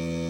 mame/samples/floppy/35_seek_12ms.wav at 67be13960b2fffa5460c3e5dbb0a7f9736b87d3e